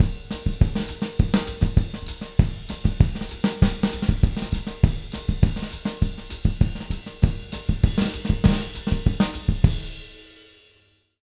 Index of /90_sSampleCDs/USB Soundscan vol.08 - Jazz Latin Drumloops [AKAI] 1CD/Partition D/07-200 SAMBA
200SAMBA05-R.wav